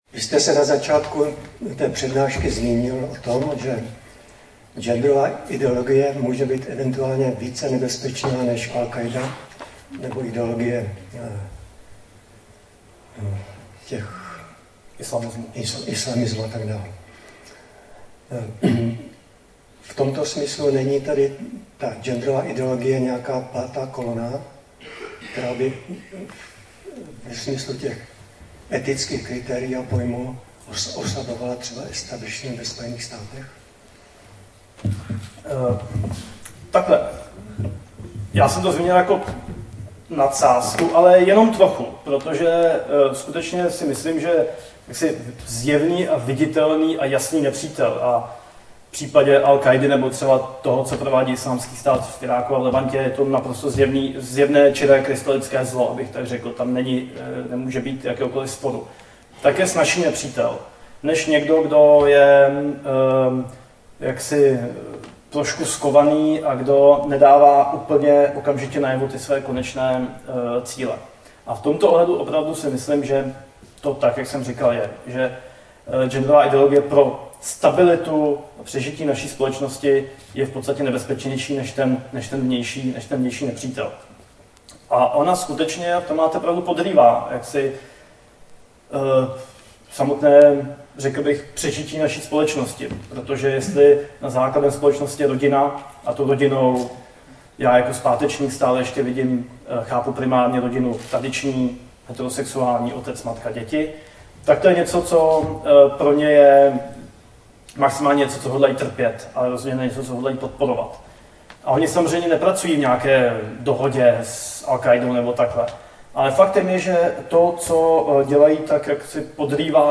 Záznam přednášky v mp3: